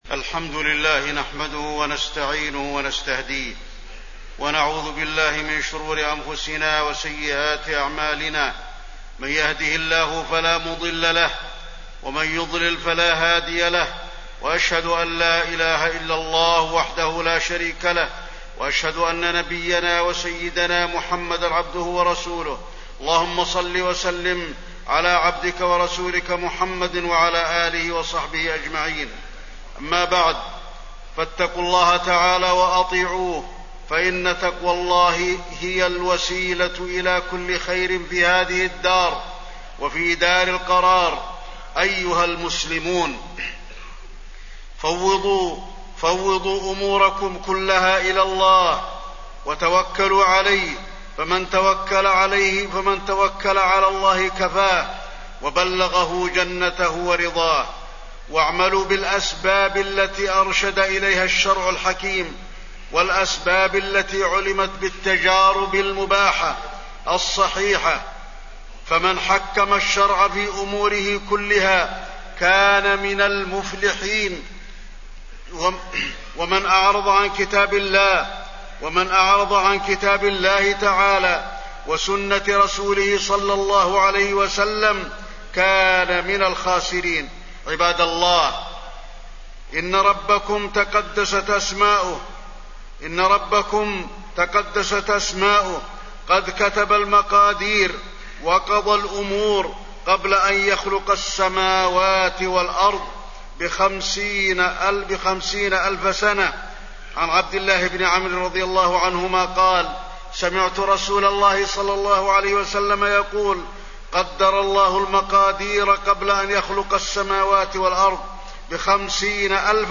تاريخ النشر ١٧ ذو الحجة ١٤٣٠ هـ المكان: المسجد النبوي الشيخ: فضيلة الشيخ د. علي بن عبدالرحمن الحذيفي فضيلة الشيخ د. علي بن عبدالرحمن الحذيفي التوكل